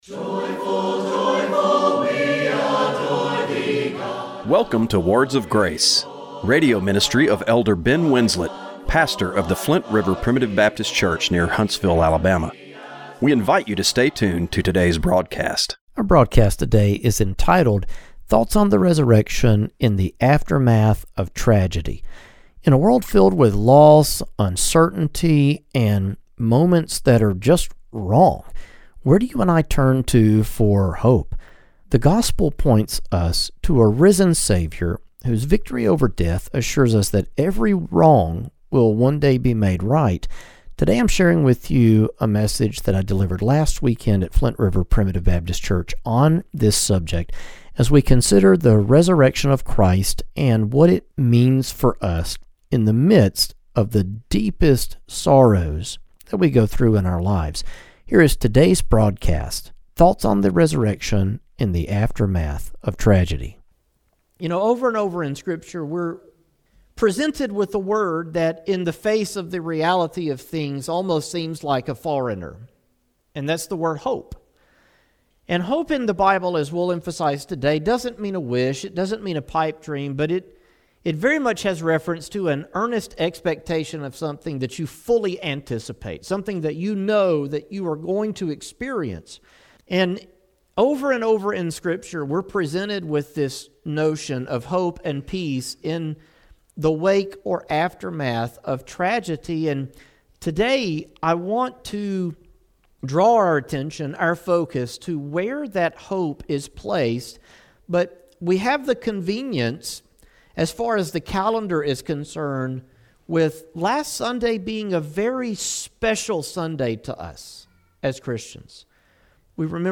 Radio broadcast for April 19, 2026.